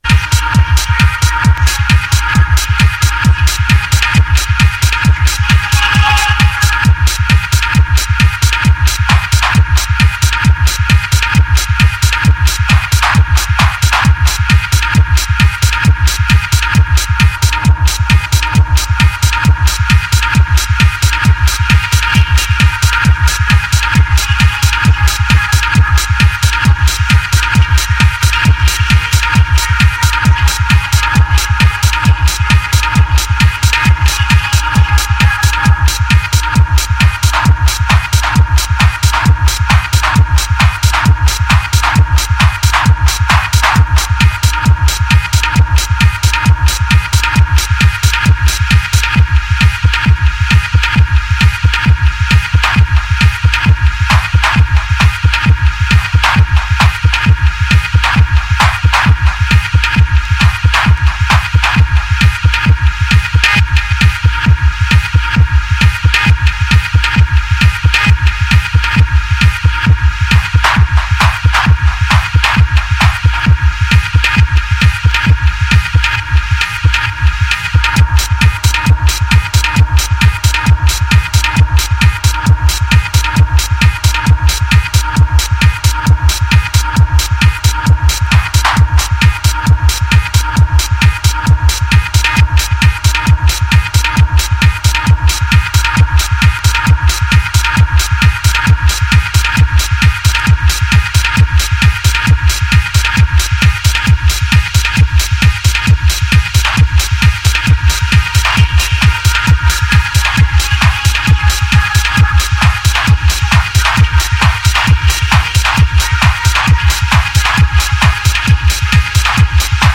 Techno Lp Reissue